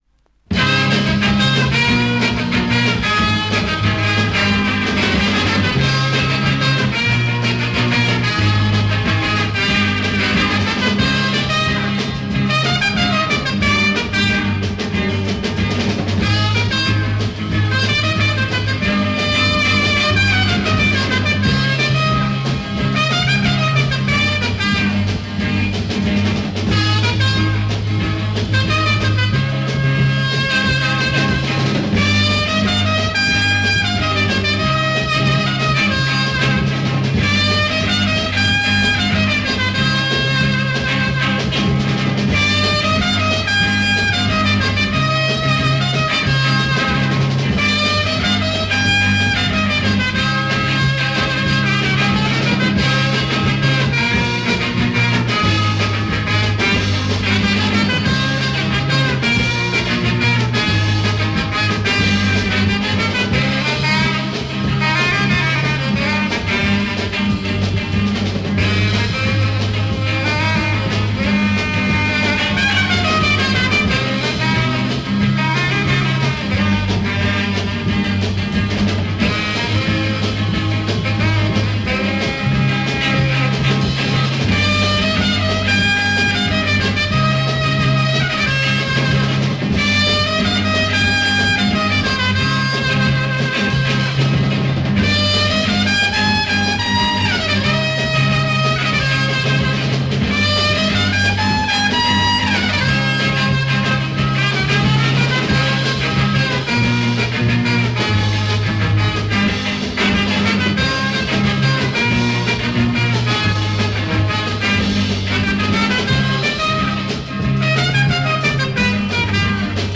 Private Garage beat French EP